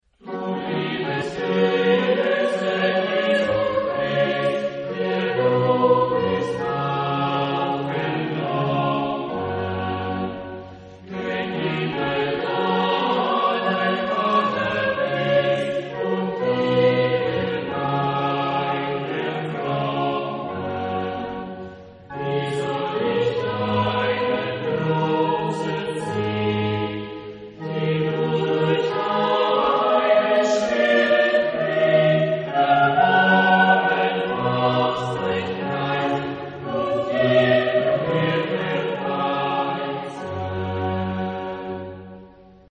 Genre-Style-Forme : Sacré ; Cantate
Type de choeur : SATB  (4 voix mixtes )
Solistes : soli
Instrumentation : Orchestre